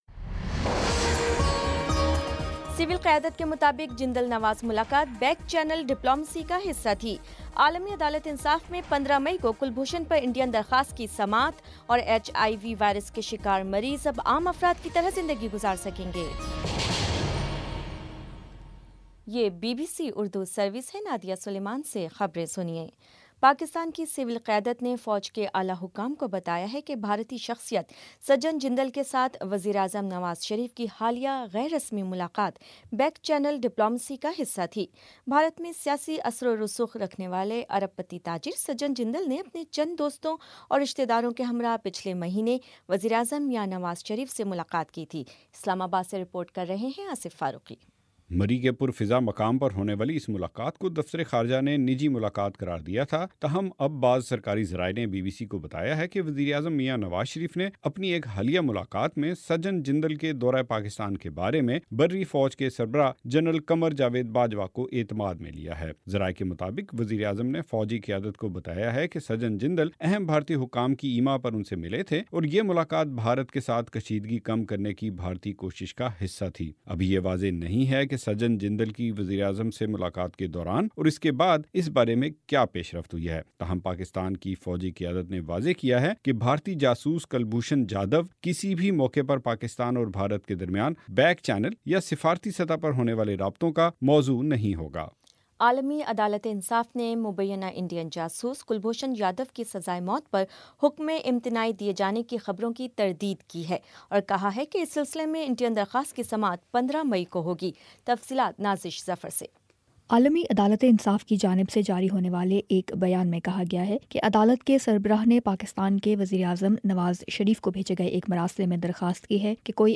مئی 11 : شام چھ بجے کا نیوز بُلیٹن